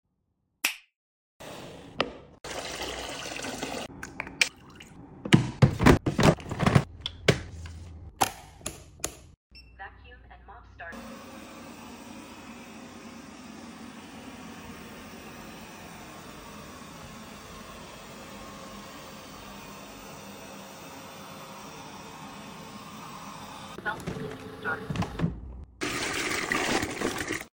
New home robot mop vacuum sound effects free download